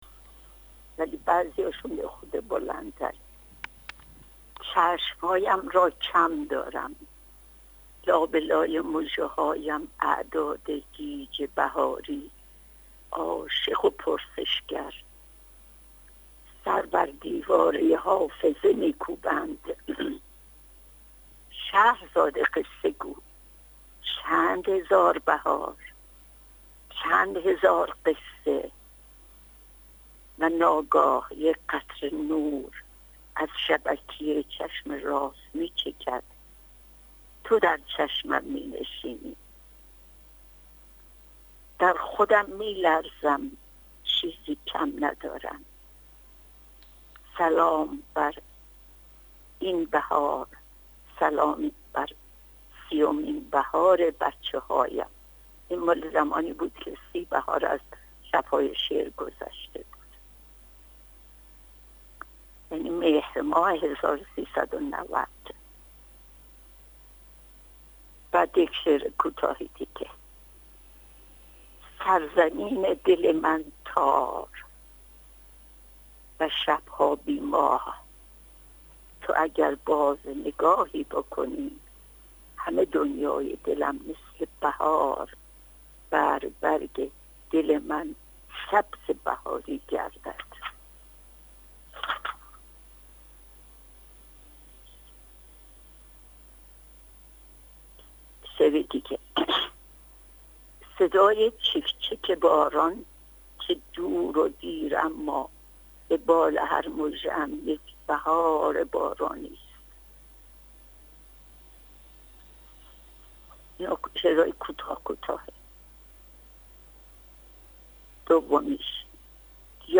از بهاریه‌هایش برای مخاطبان ایسنا خواند که می‌توانید آن‌ها را در ادامه بشنوید.